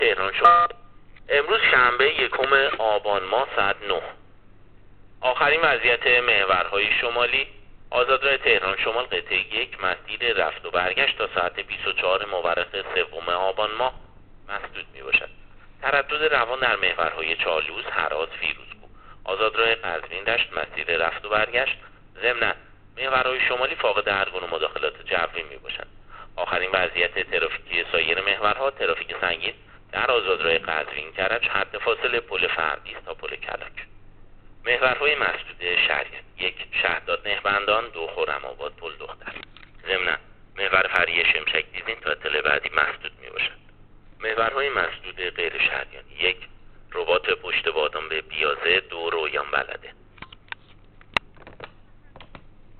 گزارش رادیو اینترنتی از آخرین وضعیت ترافیکی جاده‌ها تا ساعت ۹ اول آبان؛